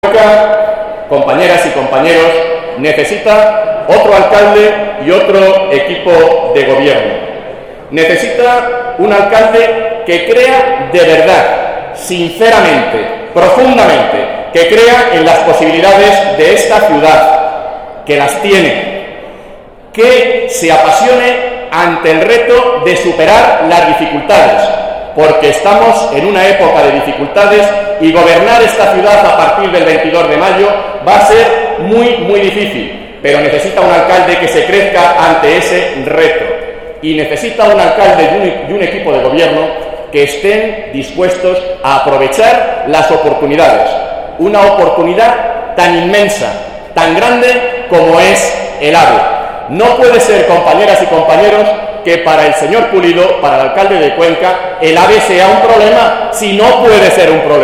Cerca de 600 personas han acudido a la comida de Navidad que el PSOE de ha ofrecido en la capital, sin embargo, tres acto de similares características se ha celebrado también este fin de semana en Tarancón, San Clemente y Quintanar del Rey.